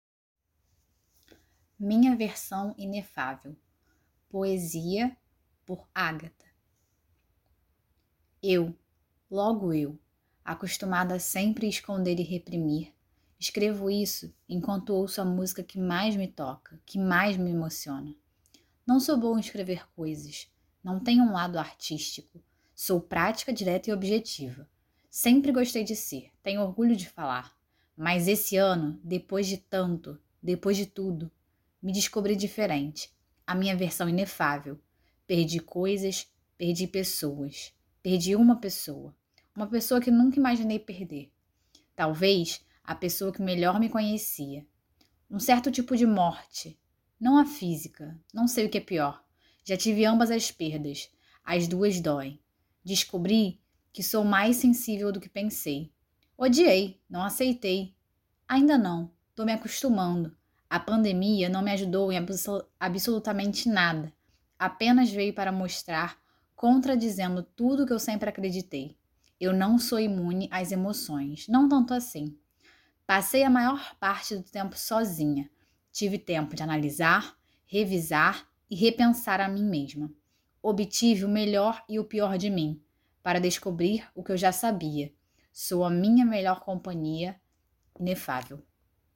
Técnica: poesia
Poesia com voz humana